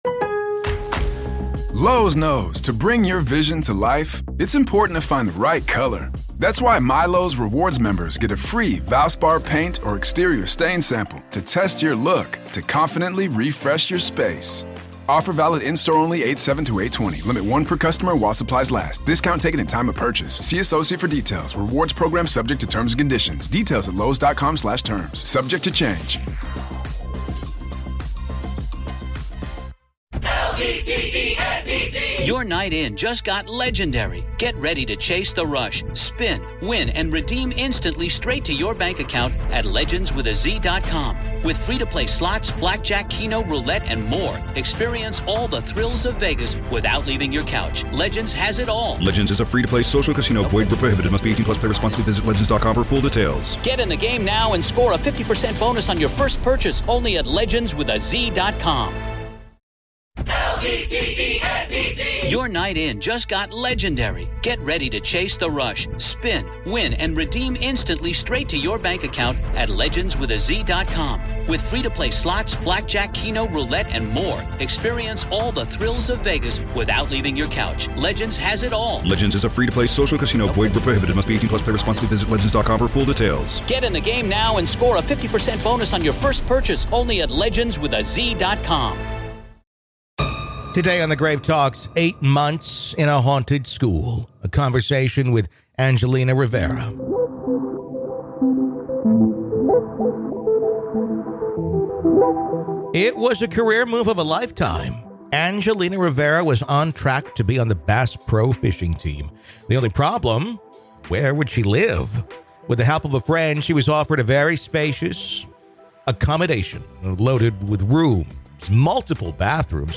Interview 🪦 Grave Talks Classic